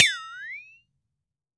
FLEXATONE  4.WAV